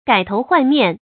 gǎi tóu huàn miàn
改头换面发音
成语注音 ㄍㄞˇ ㄊㄡˊ ㄏㄨㄢˋ ㄇㄧㄢˋ